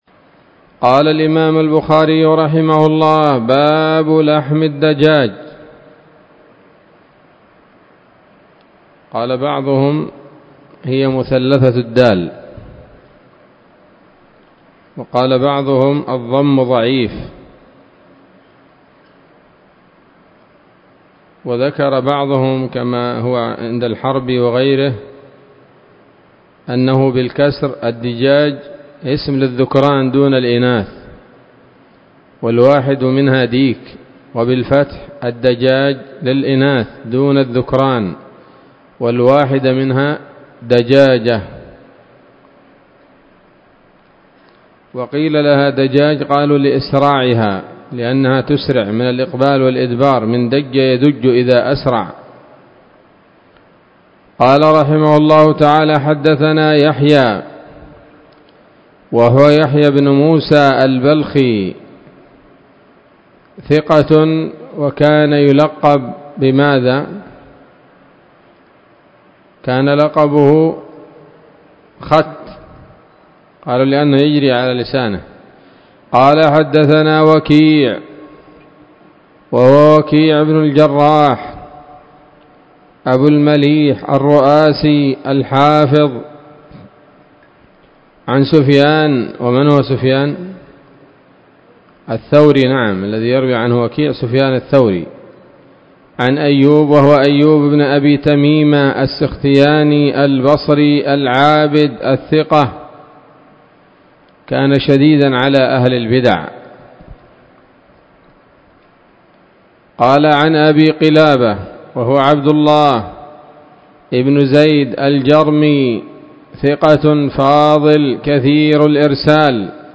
الدرس الثاني والعشرون من كتاب الذبائح والصيد من صحيح الإمام البخاري